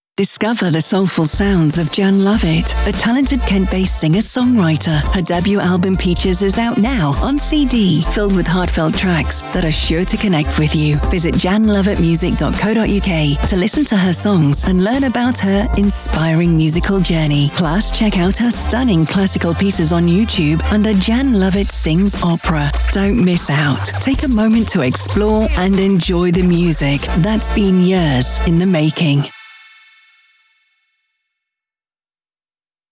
The radio advertisement is being played regularly on the Made in Kent Radio station (download the app from your favourite place).
Radio advertisement made by Made in Kent Radio